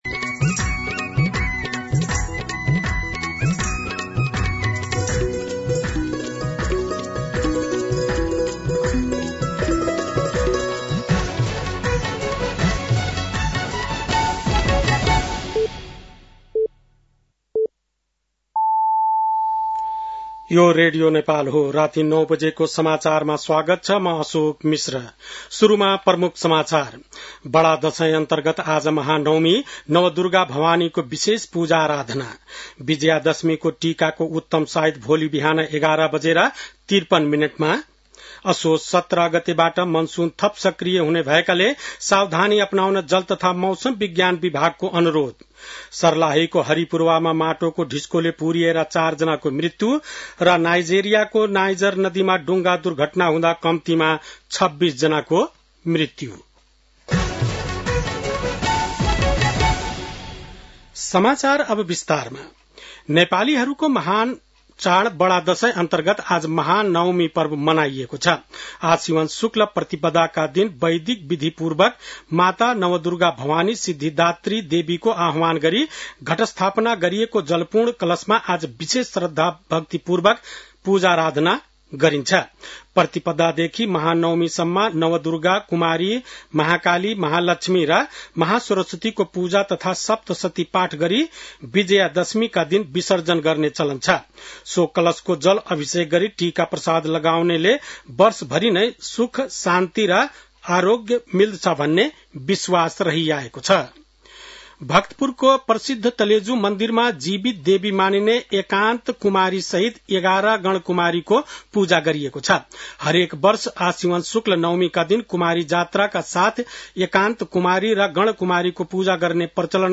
बेलुकी ९ बजेको नेपाली समाचार : १५ असोज , २०८२
9-pm-nepali-news-6-15.mp3